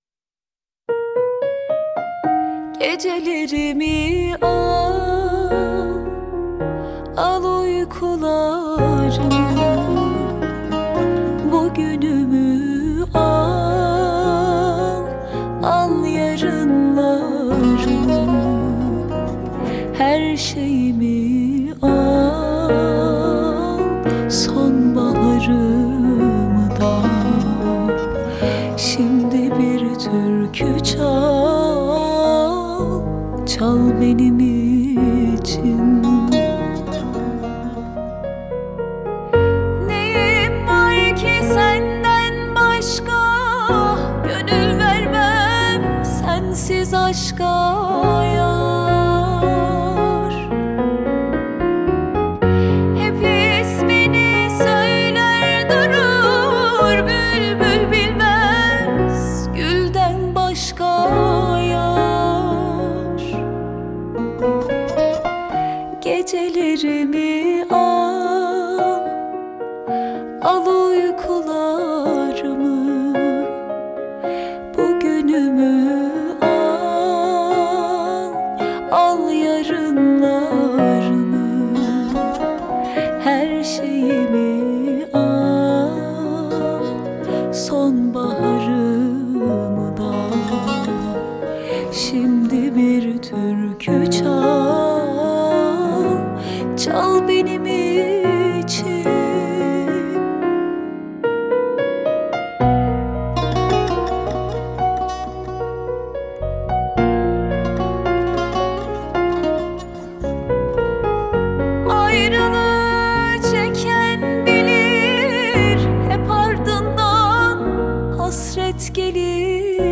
Piano
Bağlama
Bas Gitar